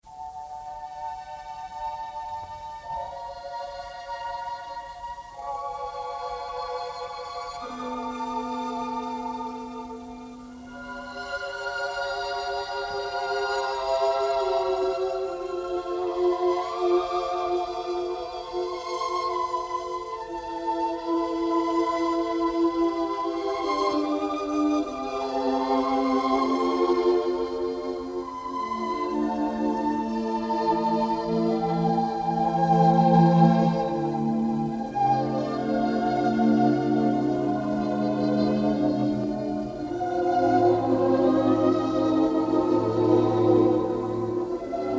a gorgeous score brimming with sympathy and melancholy.
The sound quality is largely "archival,"